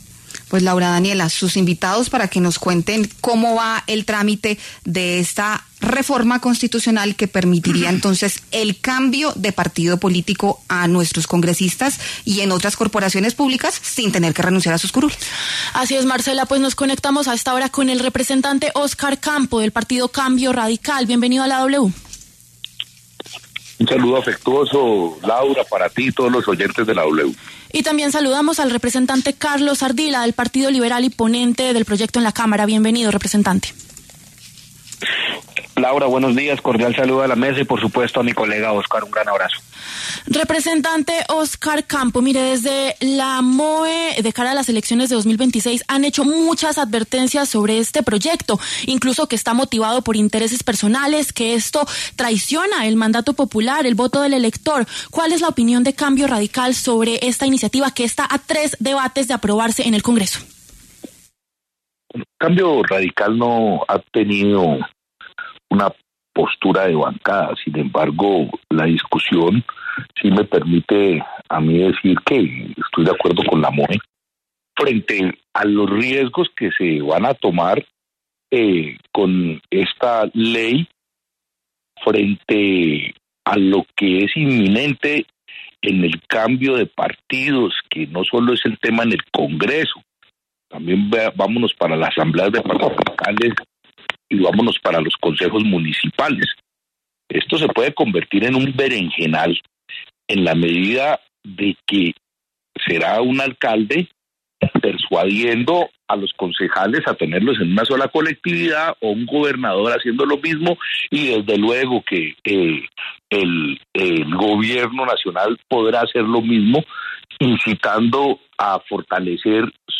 Los representantes Carlos Ardila y Óscar Campo debatieron en La W sobre el proyecto de transfuguismo, que está a tres debates de aprobarse en el Legislativo, y sobre las discusiones que se suspendieron esta semana por el receso de Semana Santa.